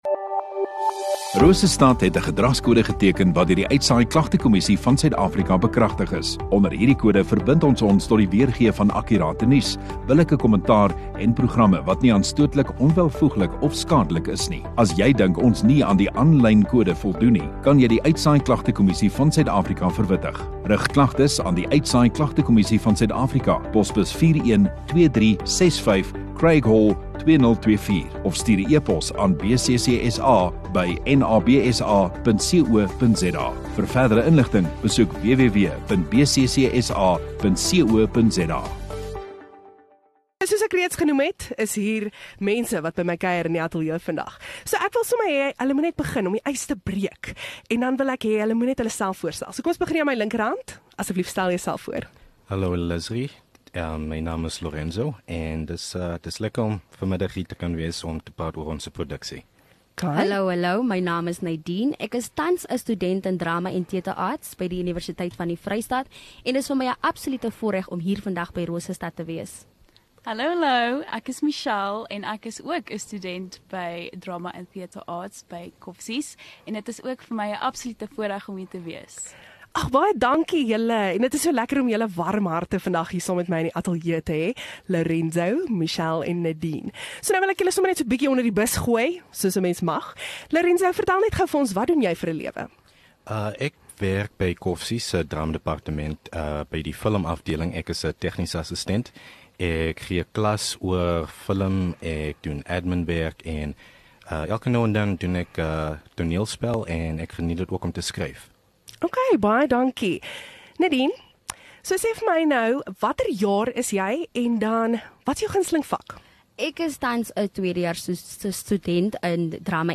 Onderhoude